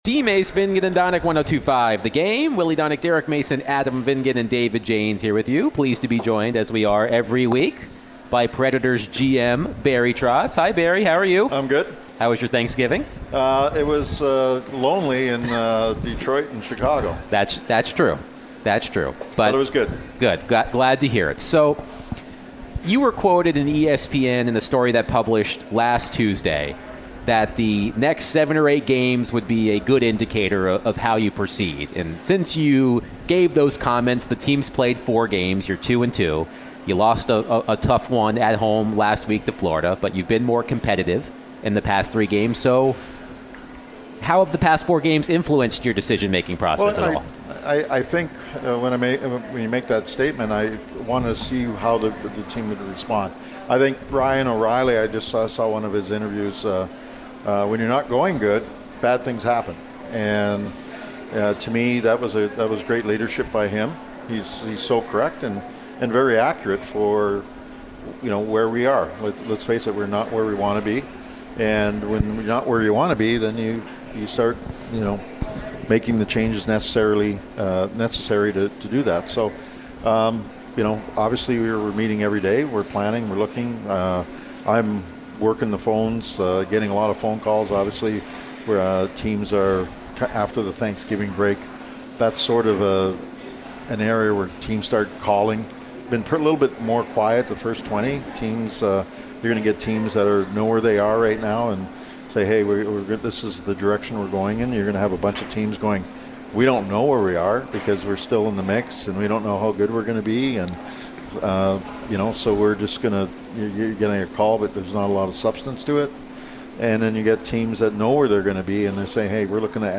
Nashville Predators GM Barry Trotz joined DVD for his weekly conversation